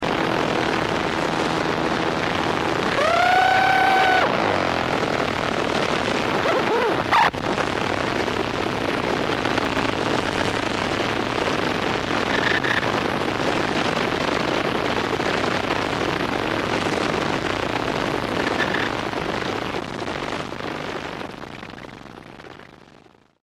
Le registrazioni originali su cassette sono tuttora conservate nel mio archivio, e le digitalizzazioni degli anni 1993-1995 sono state effettuate con software buoni sebbene non eccelsi, e a risoluzioni "decenti" a 44Khz.